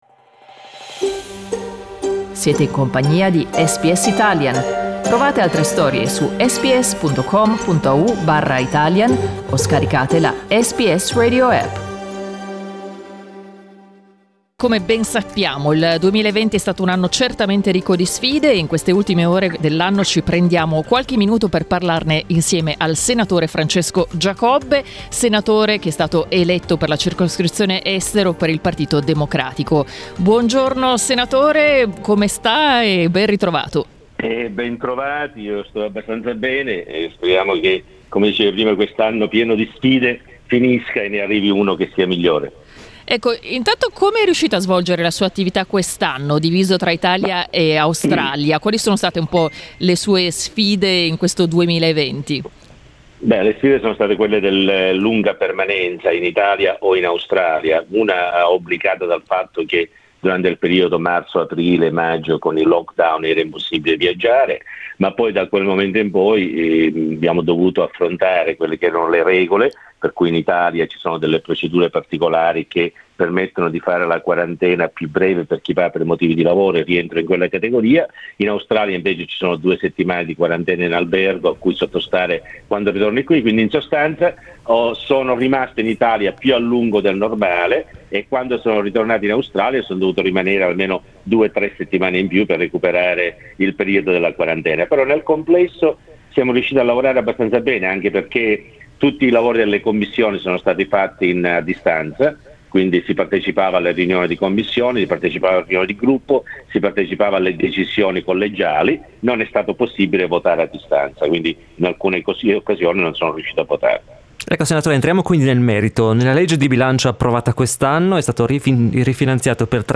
Da Sydney il Senatore Francesco Giacobbe, eletto nel 2018 nella Circoscrizione Estero che comprende Africa, Asia, Oceania e Antartide, tira le somme di un anno complesso ma non privo di buoni risultati per la comunità italiana all'estero.
SKIP ADVERTISEMENT Il senatore Francesco Giacobbe le ha riassunte ai nostri microfoni.